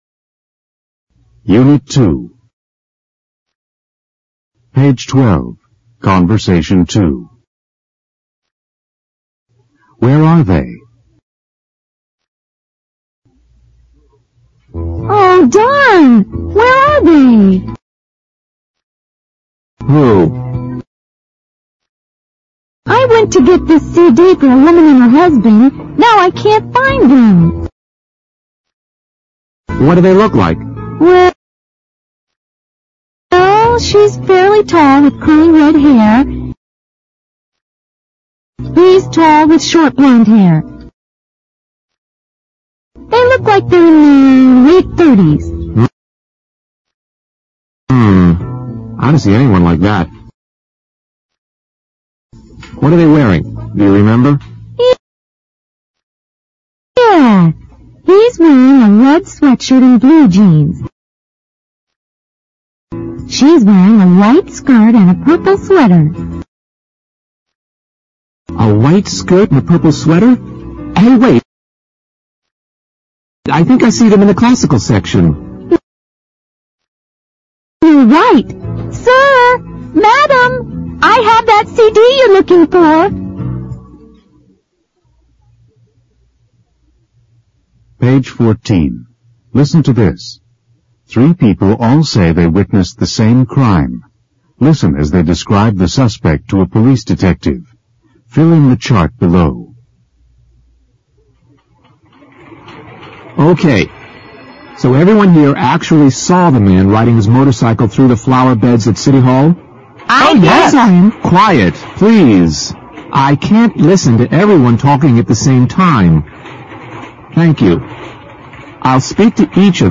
简单英语口语对话 unit2_conbersation2_new(mp3+lrc字幕)